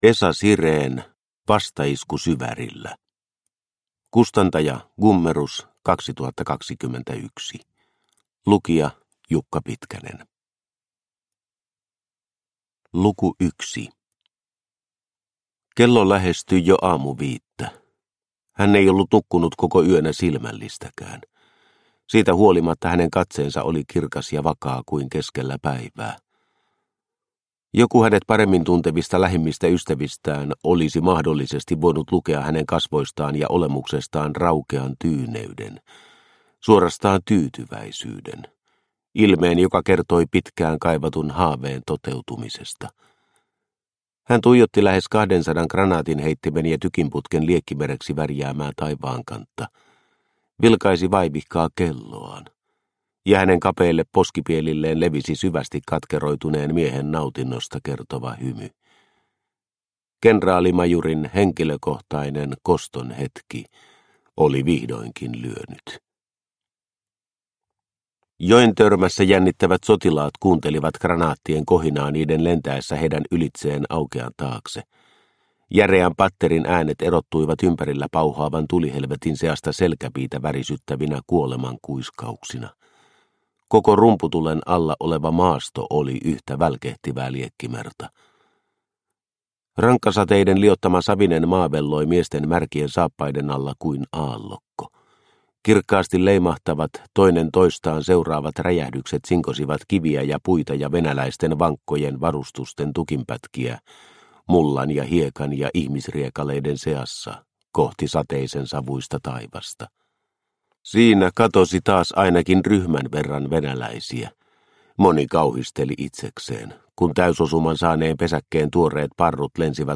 Vastaisku Syvärillä – Ljudbok – Laddas ner